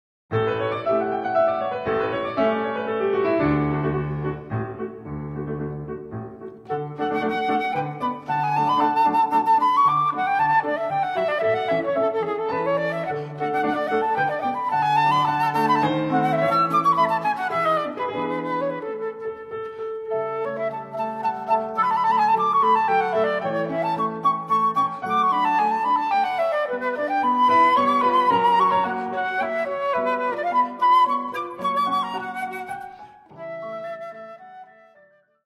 for the flute